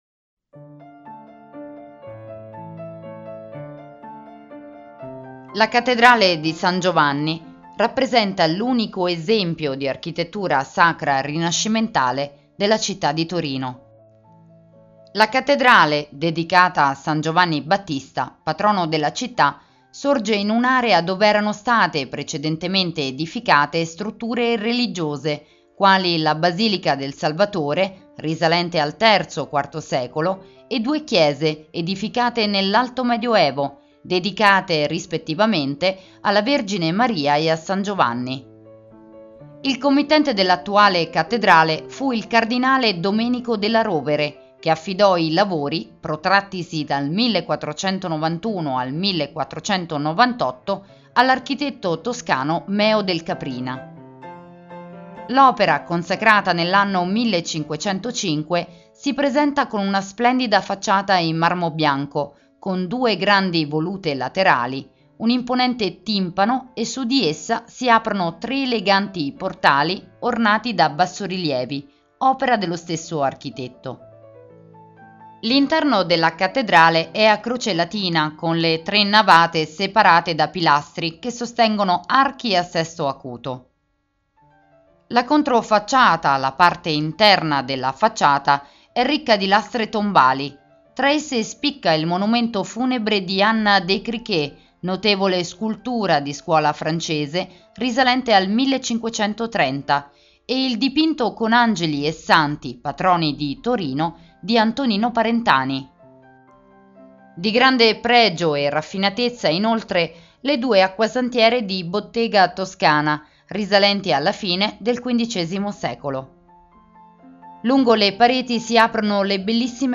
Audioguida Torino – Cattedrale di San Giovanni